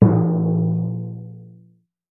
Звуки мультяшных ударов
Комический удар по животу